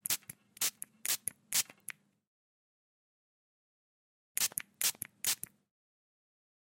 Звуки пульверизатора
Звук пшиканья из распылителя